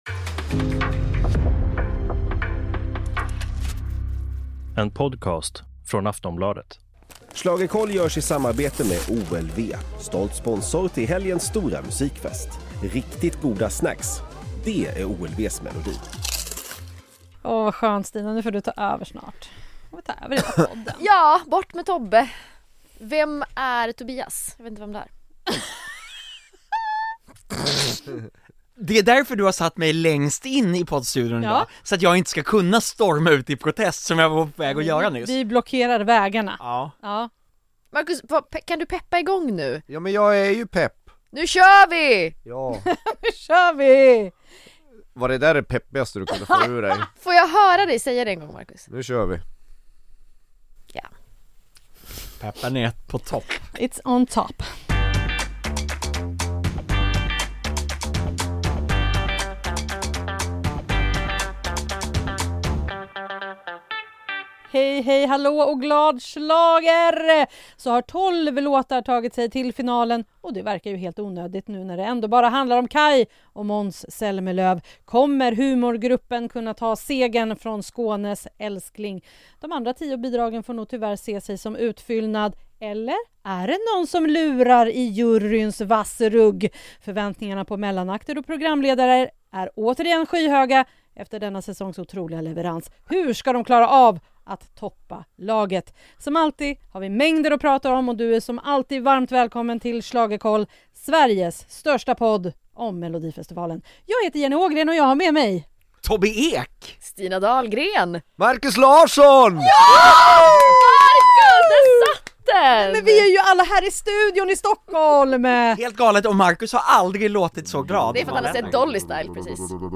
Hela kvartetten är samlad i studion i Stockholm för att diskutera och analysera startfältet.
(Ja, förlåt, det blev en del prat i munnen på varandra - vi skyller på att det är vecka sex av tävlingen).